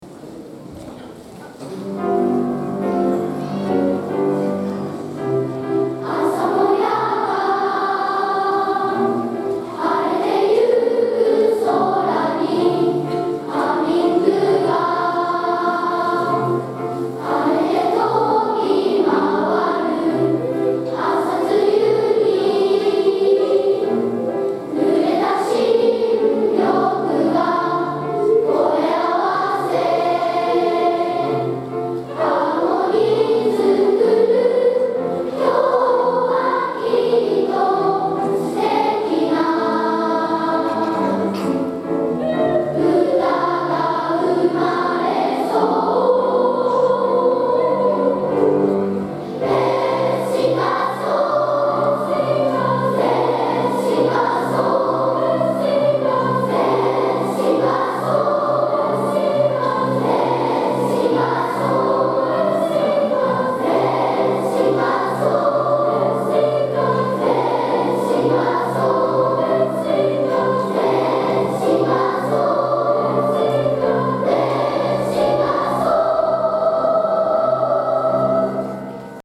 2018年2月24日（土）大空ありがとうコンサート
さわやかなハーモニーが会場中をつつみます♪